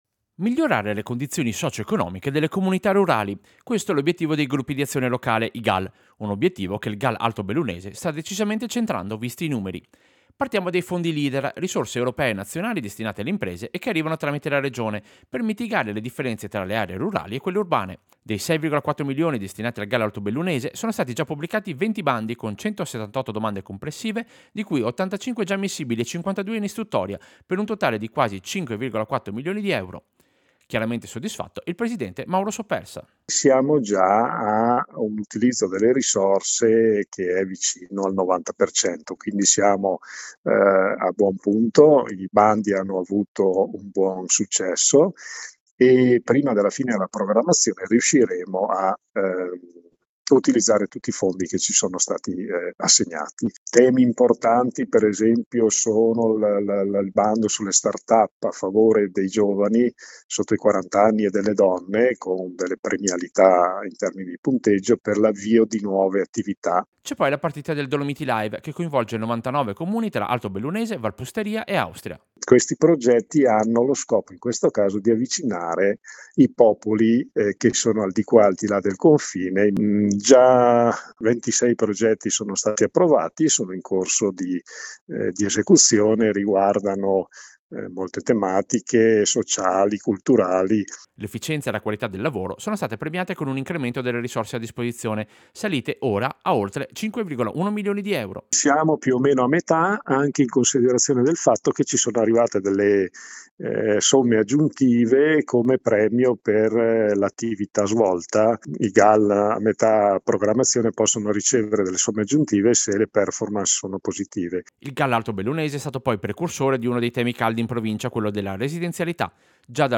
Servizio-Attivita-GAL-Alto-Bellunese.mp3